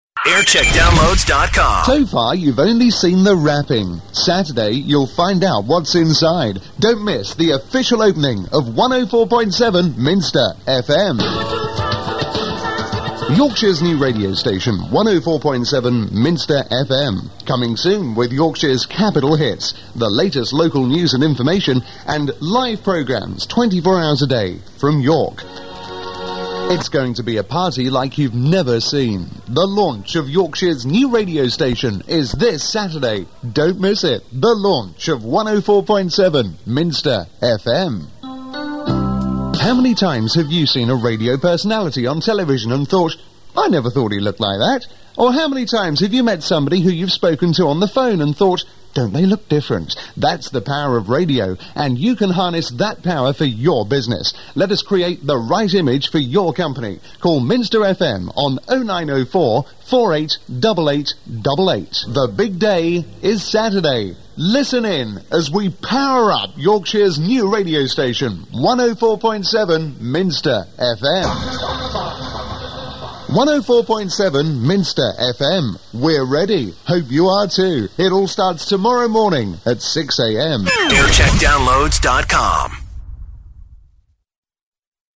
Test TX July 3rd 1992 (1'22) - Minster FM Yorkshire